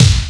Kick_05.wav